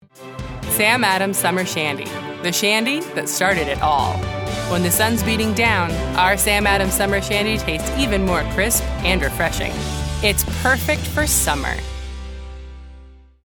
Sam Adams Radio Ad Demo
My voice is warm and comforting, relatable, humorous, and authoritative. As a natural alto I am very comfortable in low, smokey and intimate ranges.